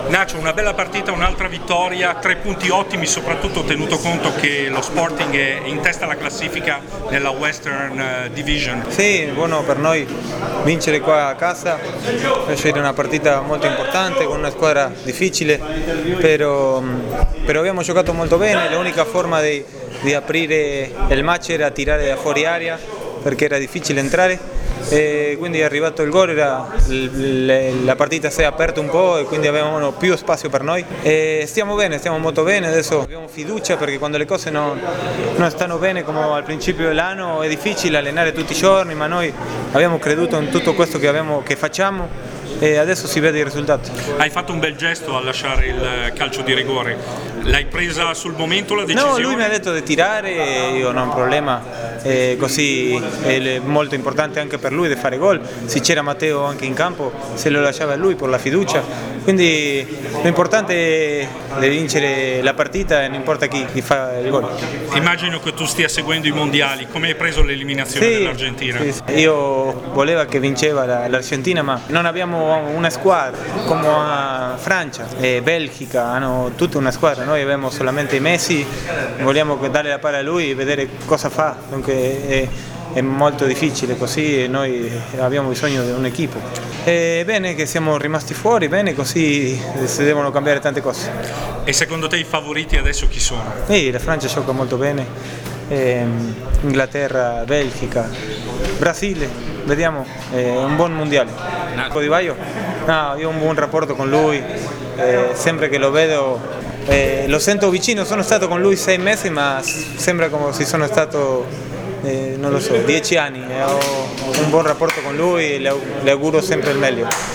Le interviste post-partita: